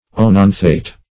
Search Result for " oenanthate" : The Collaborative International Dictionary of English v.0.48: oenanthate \oe*nan"thate\, oenanthate \oe*nan"thate\, n. (Chem.) A salt or ester of oenanthic acid; as, testosterone oenanthate is sold as an anabolic steroid; also called enanthate .